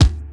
clear-steel-kick-drum-sound-d-key-152-7A3.wav